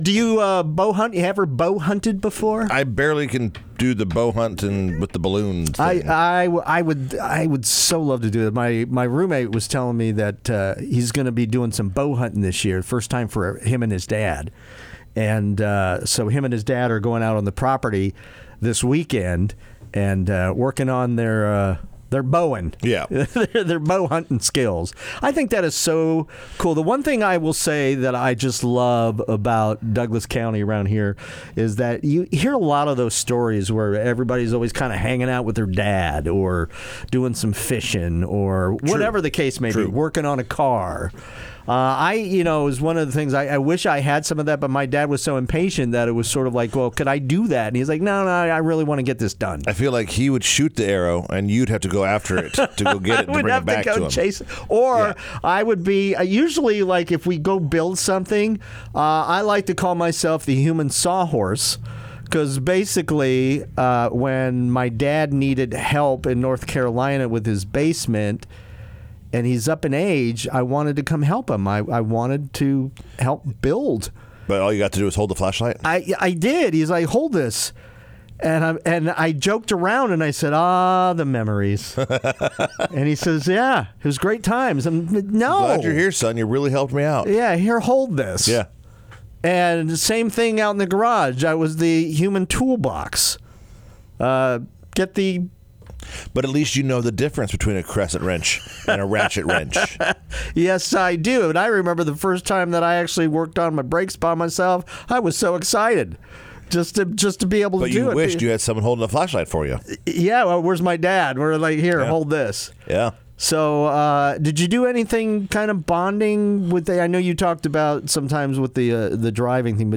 Listeners join in on the fun as well.